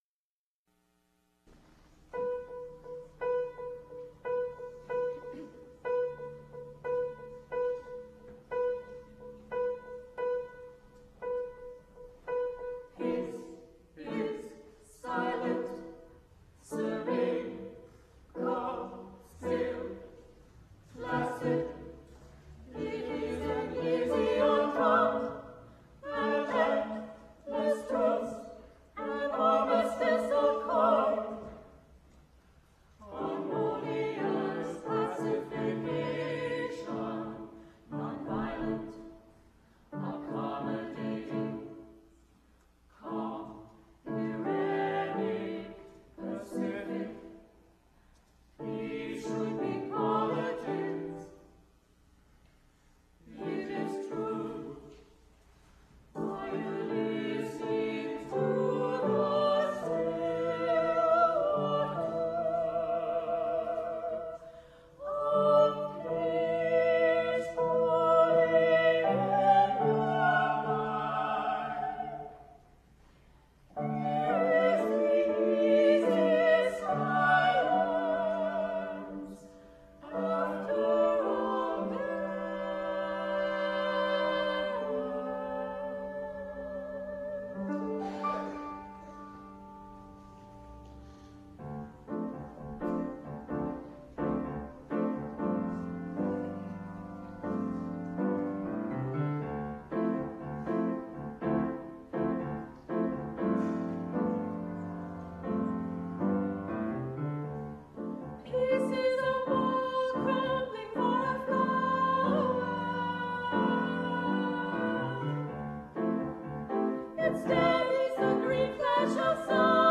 This resulted in a remarkable 18 team-proposals for new works, six of which were chosen to go forward to a final round: public readings of "works-in-progress" by the artist-teams.